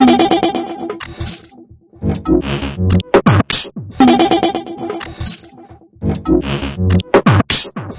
描述：An experimental loop with a melodic 'basic channel' touch created with Massive within Reaktor from Native Instruments.
标签： 120bpm drumloop experimental loop minimal
声道立体声